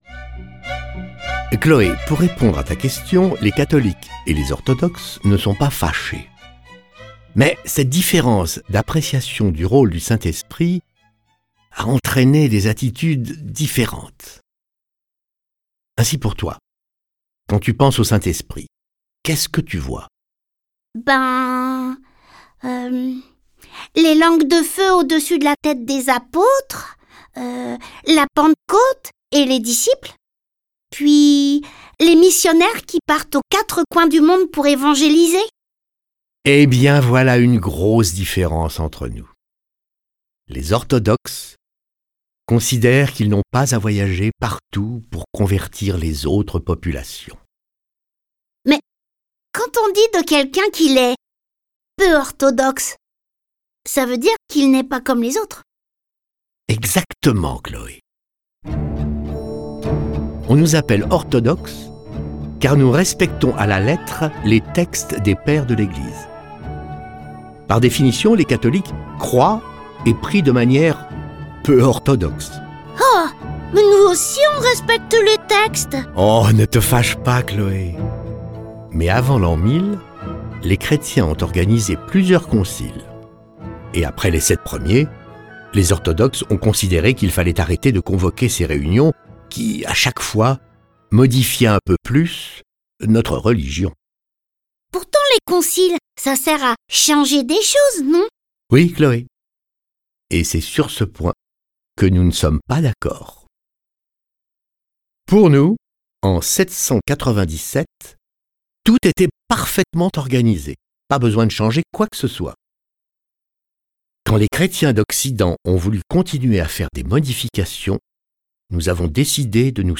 Diffusion distribution ebook et livre audio - Catalogue livres numériques
Il leur présente aussi la liturgie spécifique à cette religion et ses 7 mystères. Ce récit est animé par 5 voix et accompagné de plus de 30 morceaux de musique classique et traditionnelle.